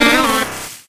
Cries
ODDISH.ogg